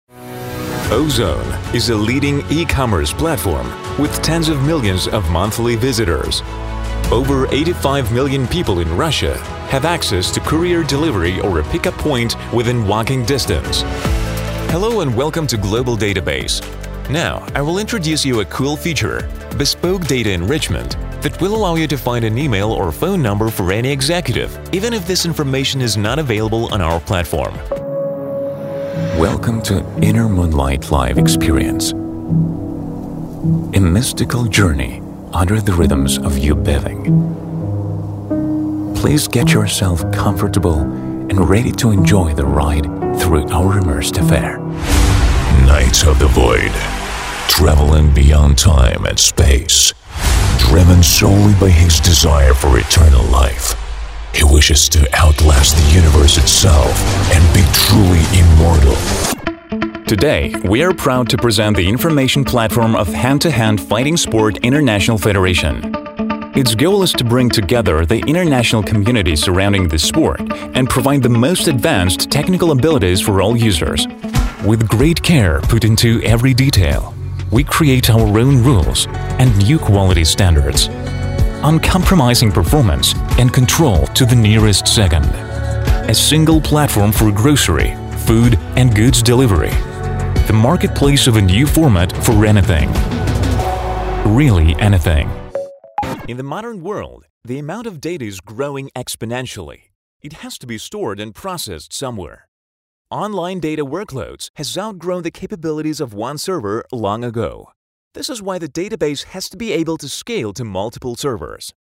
Профессионально озвучиваю тексты на английском языке. Информационный, игровой баритон. Разная актерская подача в зависимости от задачи: серьезный, брутальный, информационый, веселый, молодежный...
Английский - американский акцент, близкий к носителю.
Тракт: Neumann U87 + Aphex tube preamp Домашняя студия: Rode NT1A, предусилитель/АЦП Focusrite Scarlett 2i4, акустическая кабина